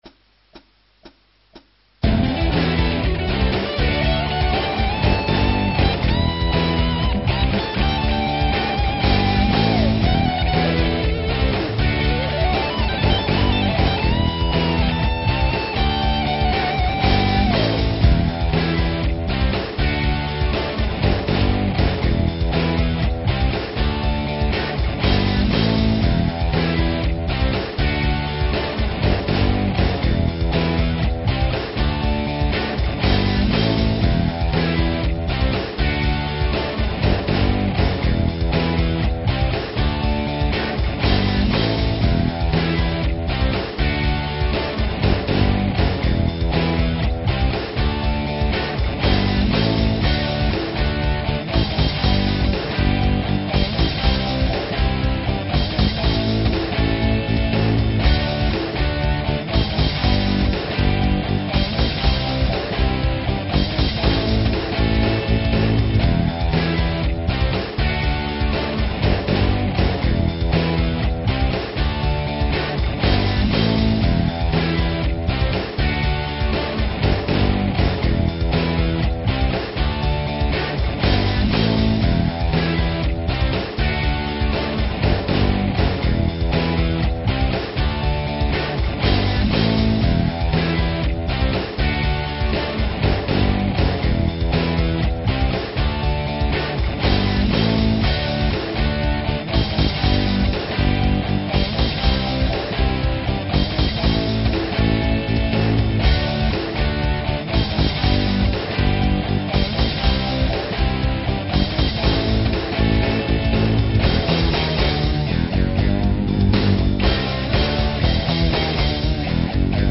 ハードブルース系